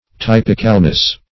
Typ"ic*al*ness, n.